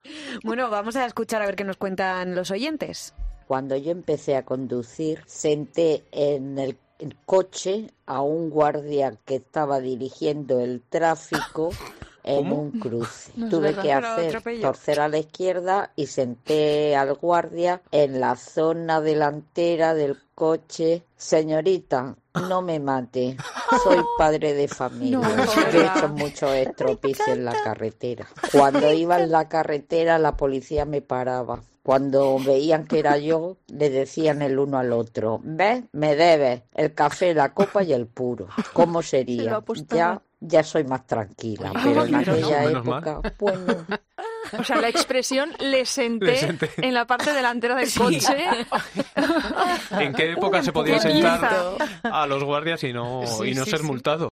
La anécdota de una oyente de COPE con su primer coche que ha provocado la risa en Fin de Semana: "Cómo sería"
"Cuando iba por la carretera, la policía me paraba. Cuando veían que era yo, le decía uno al otro: '¿ves? Me debes el café, la copa y el puro. Cómo sería...Ya soy más tranquila, pero en aquella época..." explicaba esta oyente, haciendo que todo el equipo soltara una carcajada.